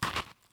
sfx_foot_sand_medium.wav